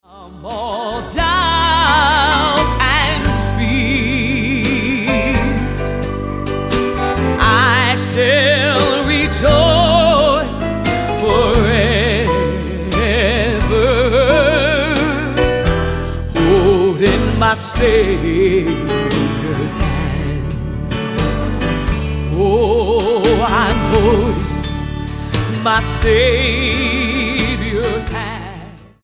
STYLE: Gospel
with a band re-creating a timeless, bluesy accompaniment